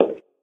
inside-step-4.ogg.mp3